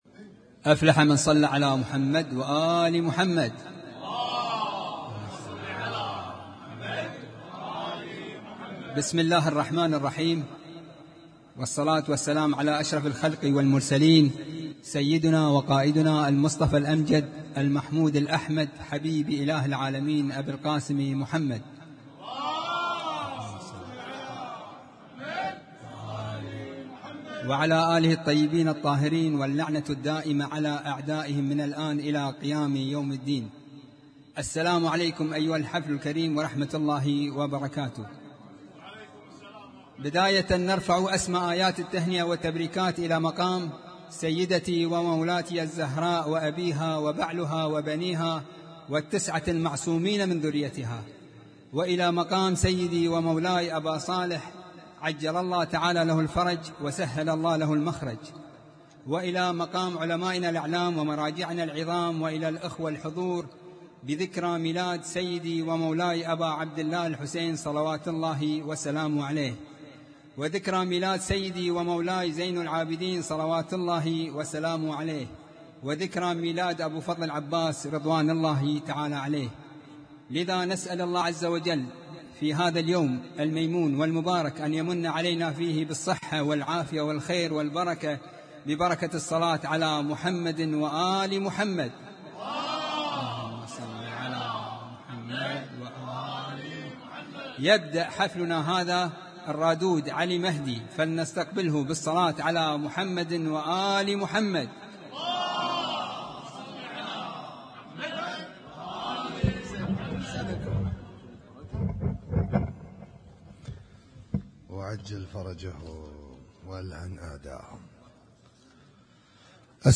Husainyt Alnoor Rumaithiya Kuwait
اسم التصنيف: المـكتبة الصــوتيه >> المواليد >> المواليد 1443